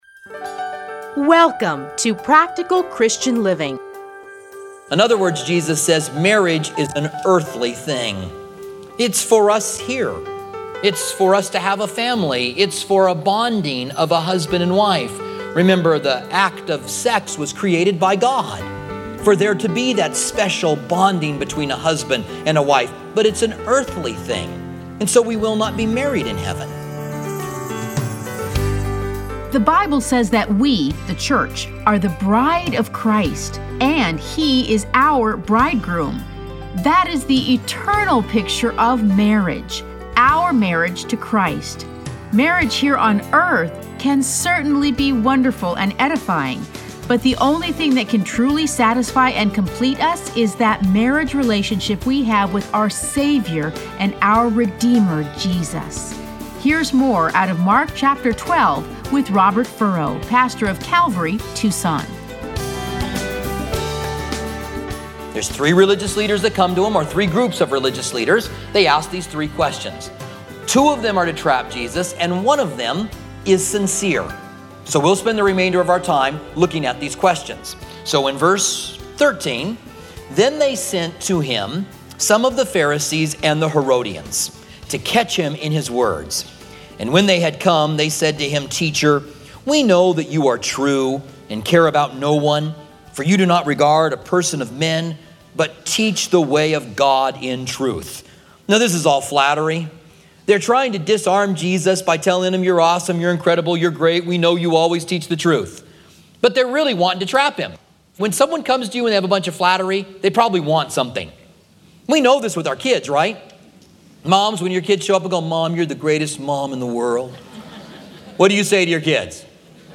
Listen to a teaching from Mark 12.